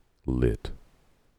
Tags: Gen Z Deep Voice Yikes